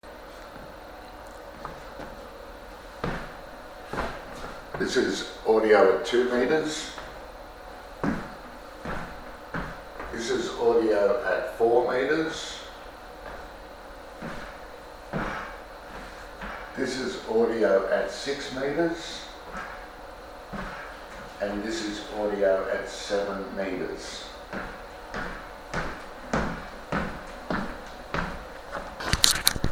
Esonic Voice Activated USB Voice Recorder
The device boasts a 8-meter or more audio range, capturing clear and crisp sound, even from a distance.
AFOMQU310-Voice-Activated-USB-Voice-Recorder-Sample-Audio.mp3